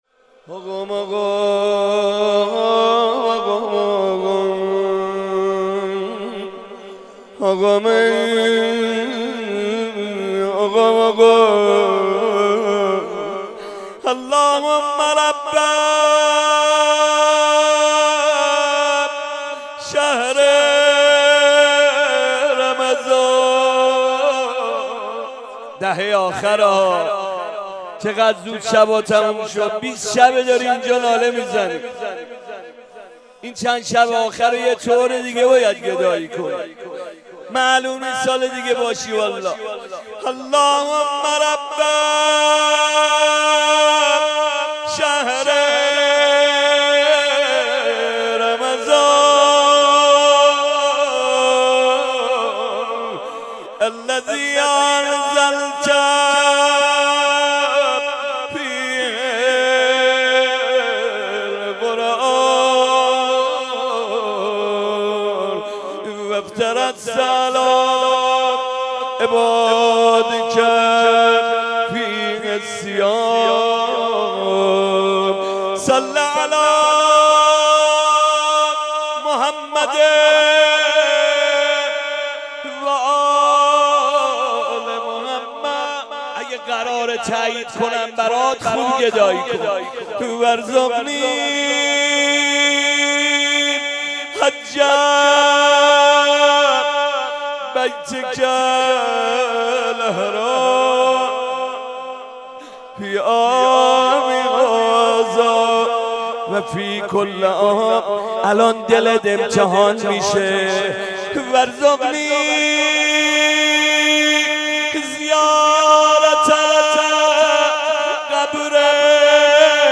مناسبت : شب بیست و یکم رمضان - شب قدر دوم
قالب : روضه مناجات